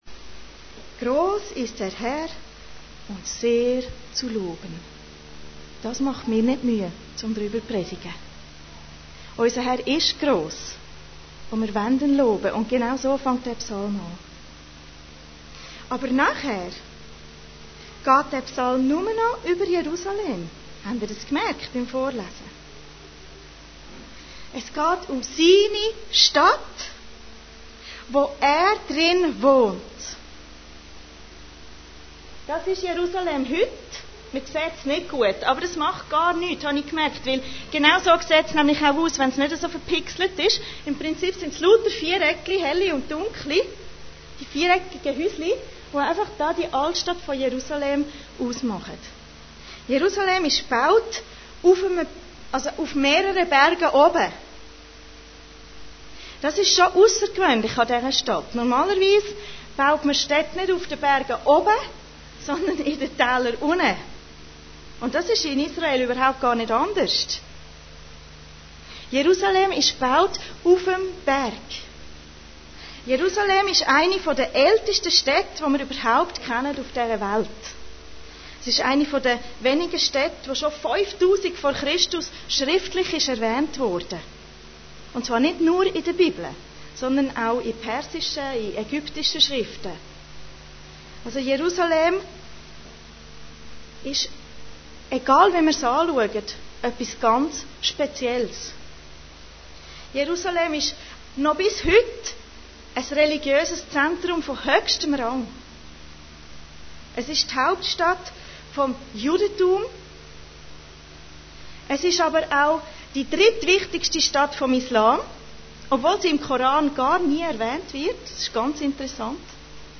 Allgemeine Predigten Date